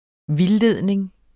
Udtale [ -ˌleðˀneŋ ]